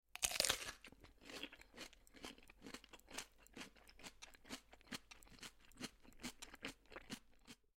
На этой странице собраны натуральные аудиоэффекты, связанные с огурцами: от хруста свежего овоща до звуков его выращивания.
Звук жующего огурца